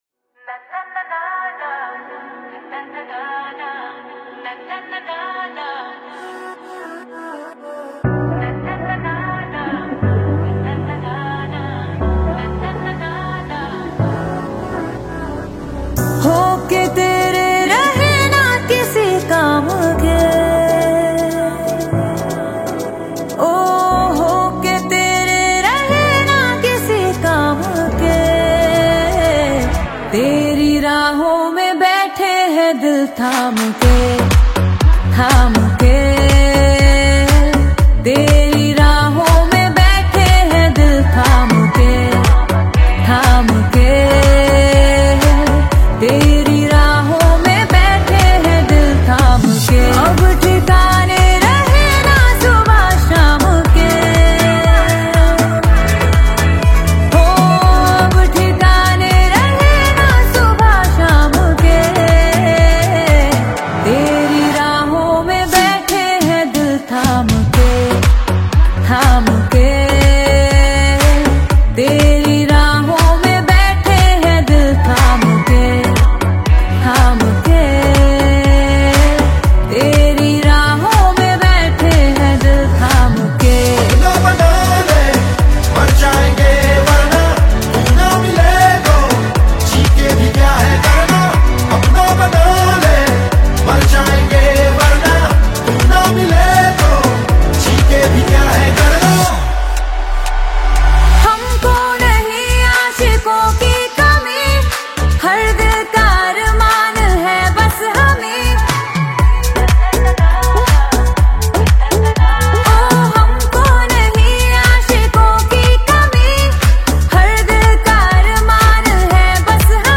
mass-level dance track
powerful vocals
Bollywood Songs